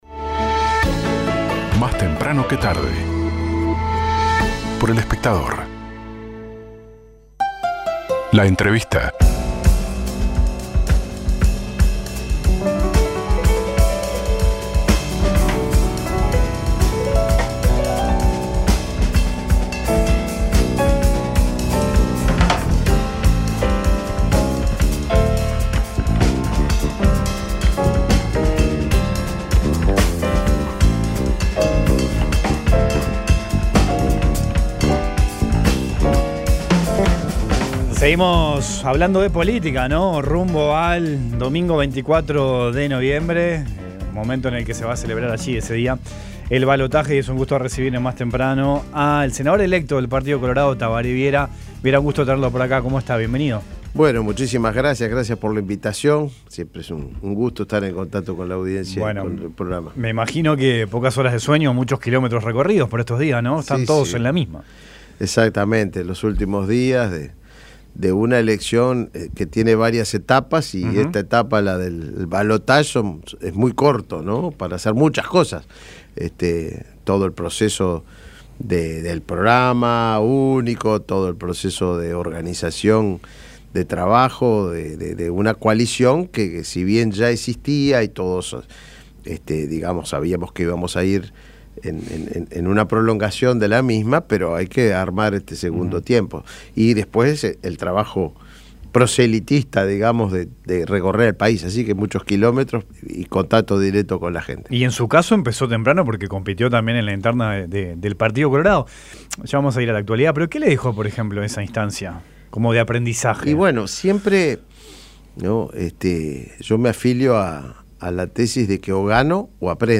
The senator elected by the Colorado Party (and former presidential candidate), Tabare Vierawas in an interview on Monday on the program Más Temprano que Tarde, on 810 AM, in which he spoke about the position that his party has in the context of the multicolor coalition.